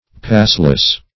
Passless \Pass"less\, a. Having no pass; impassable.
passless.mp3